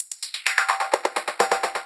Fill 128 BPM (31).wav